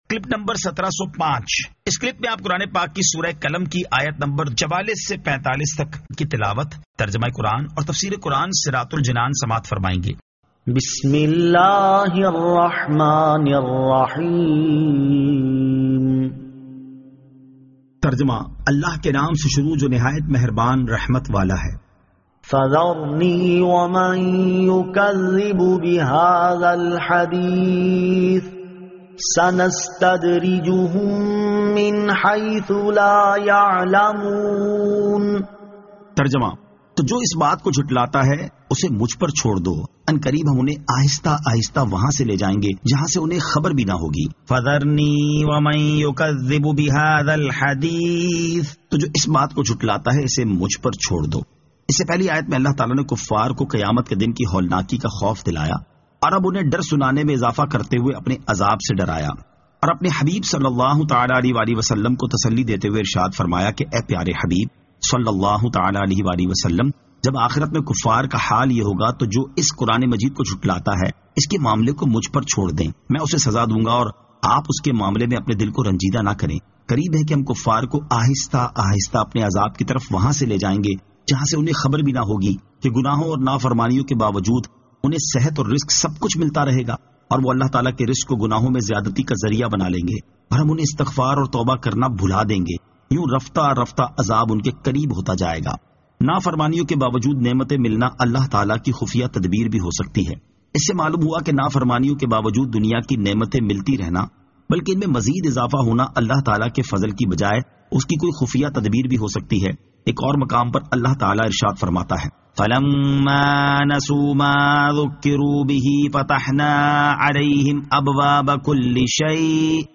Surah Al-Qalam 44 To 45 Tilawat , Tarjama , Tafseer